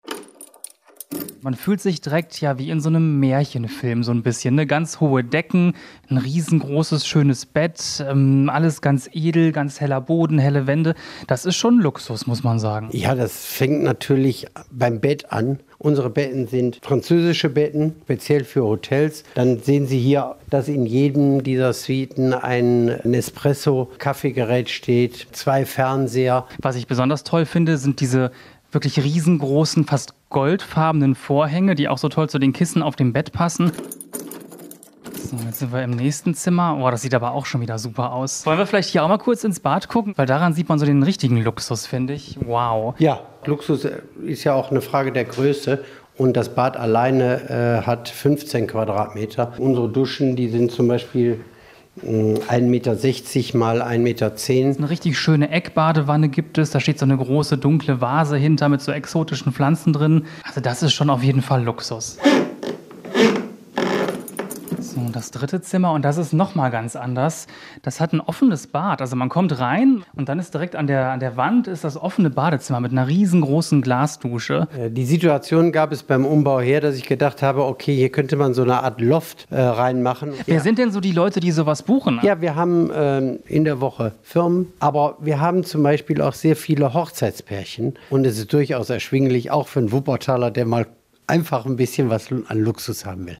_-_repo_hotel_park_villa.mp3